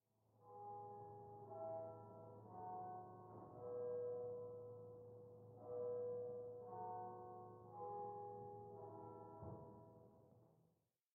01_院长房间_屋外钟声.ogg